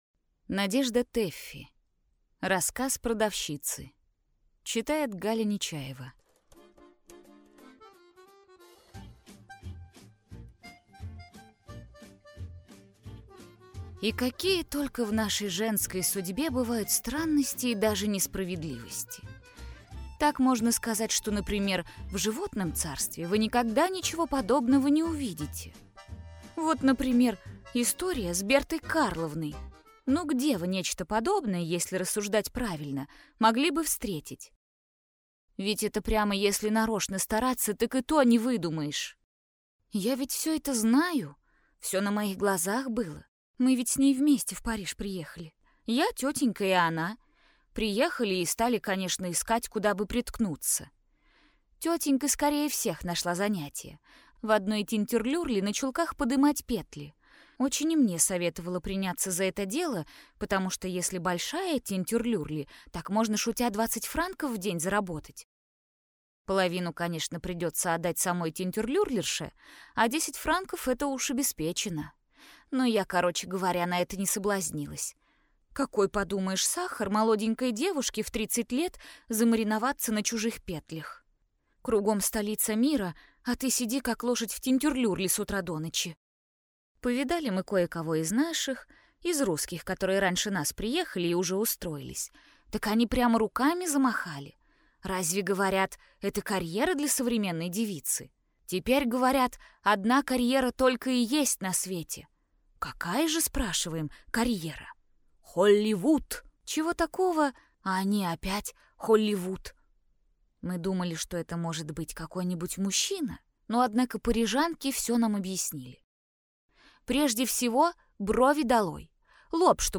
Аудиокнига Рассказ продавщицы | Библиотека аудиокниг